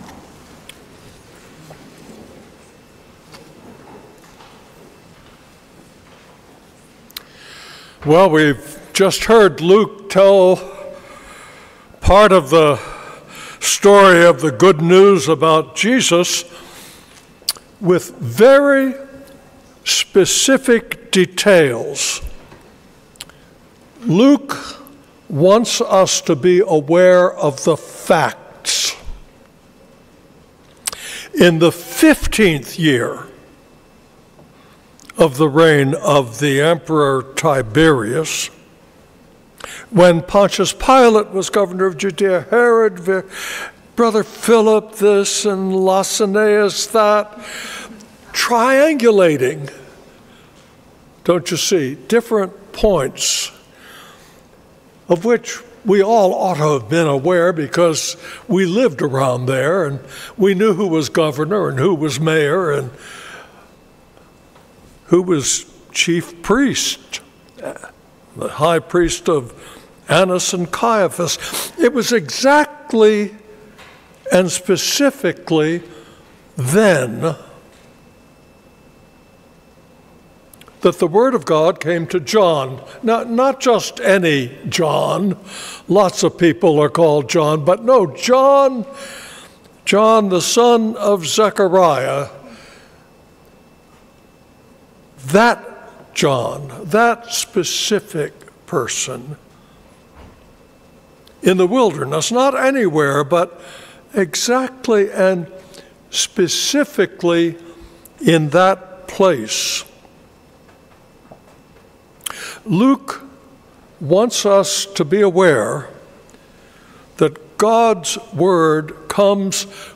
Sermons - St. Peter's Church
December-8-sermon.m4a